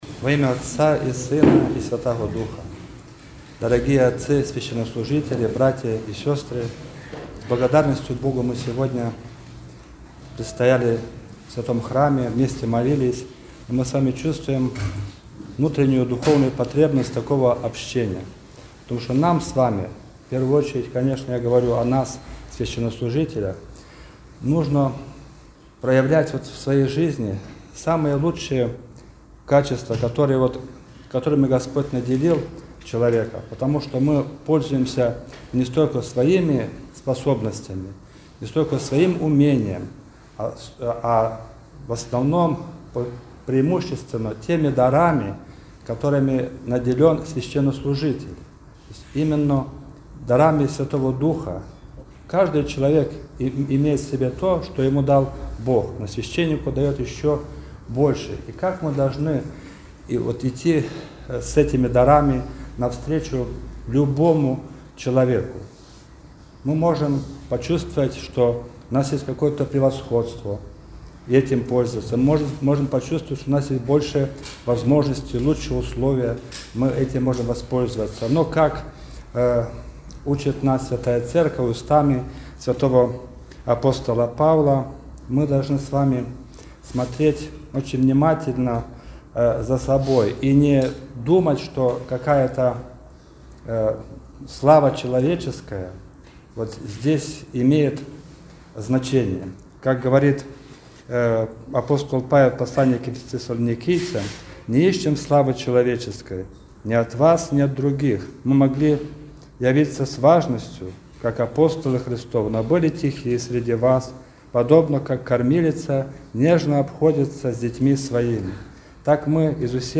31 марта 2023 года, в пятницу 5-й седмицы Великого поста, в храме святого праведного Иоанна Кронштадтского на Кронштадтской площади было совершено богослужение, в котором приняли участие клирики Красносельского благочиния.
Проповедь